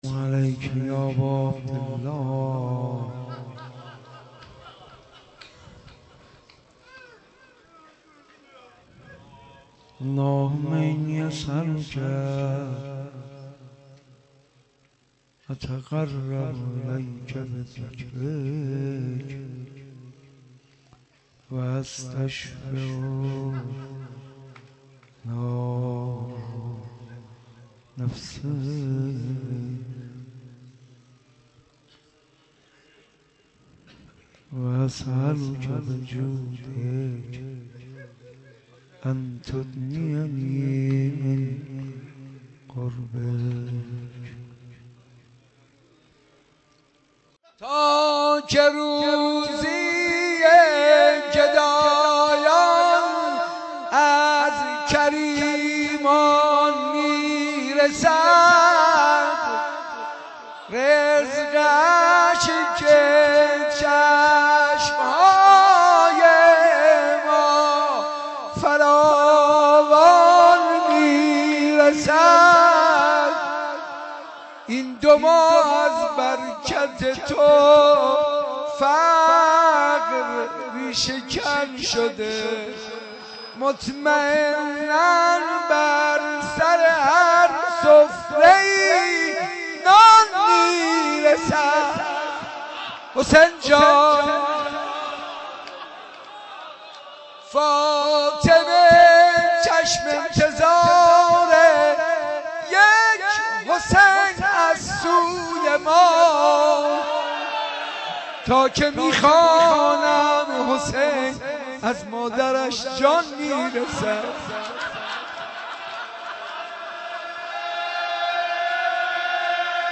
شب بیست و هفتم محرم در حسینیه بیت الزهرا
با مداحی حاج منصور ارضی برگزار شد